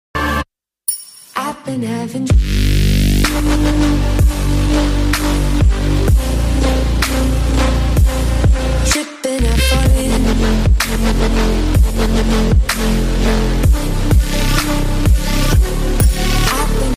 MF Harvesters Soybean Harvest ❗❗_R sound effects free download